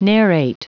Prononciation du mot narrate en anglais (fichier audio)
narrate.wav